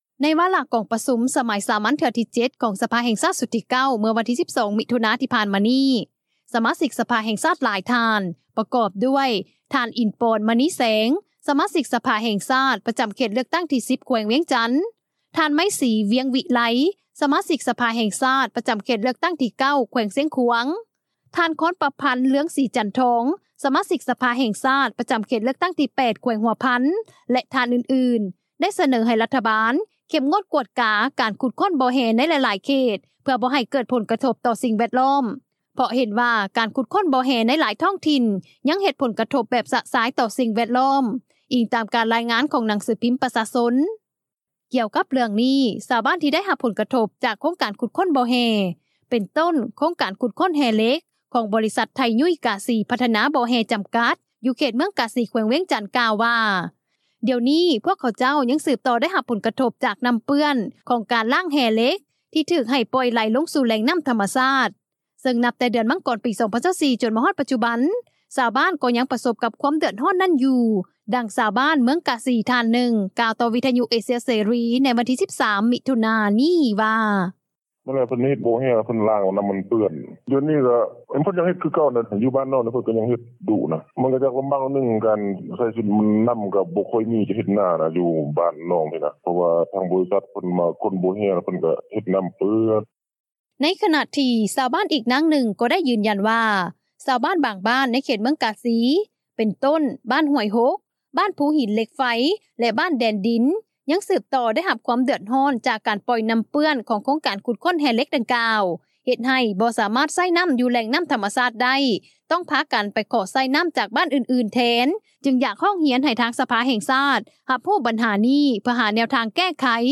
ດັ່ງຊາວບ້ານ ເມືອງກາສີ ທ່ານໜຶ່ງ ກ່າວຕໍ່ວິທຍຸເອເຊັຽເສຣີ ໃນວັນທີ 13 ມິຖຸນາ ນີ້ວ່າ:
ດັ່ງນັກວິຊາການ ດ້ານສິ່ງແວດລ້ອມ ທ່ານໜຶ່ງ ກ່າວວ່າ:
ດັ່ງຊາວບ້ານ ຢູ່ແຂວງຫົວພັນ ທ່ານໜຶ່ງ ກ່າວວ່າ: